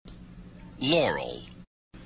If you listen to the sound that is causing all the controversy, it sounds quite a bit different than the original source.
The signal strength is noticeably higher (indicated with more yellow) in the Twitter version.
It seems to be a poor quality version of the original with extra noise and sound reflection. The sound reflection boosted the signal strength at the upper frequencies.